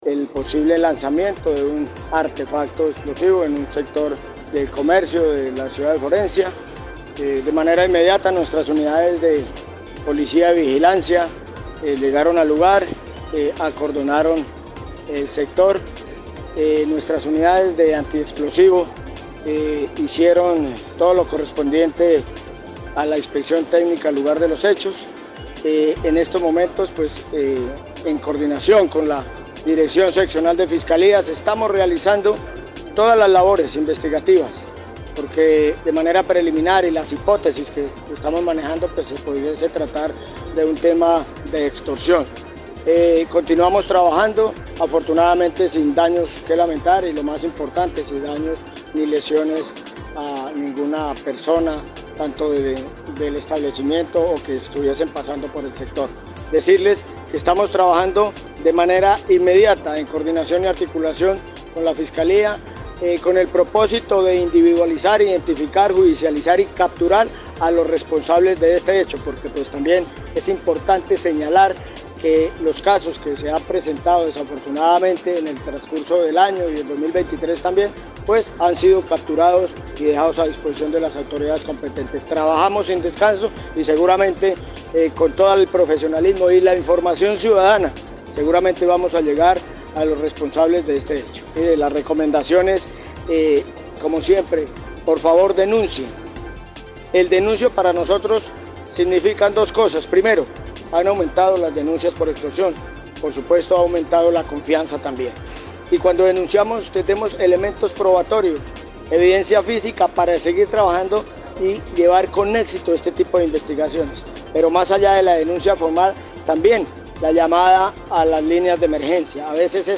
El coronel, Julio Guerrero Rojas, comandante de la Policía Caquetá, dijo que un equipo especial de Fiscalía y Policía, avanza en la investigación, misma que tiene indicios para dar con el paradero de quienes habrían cometido dicho acto de alteración del orden público en la capital.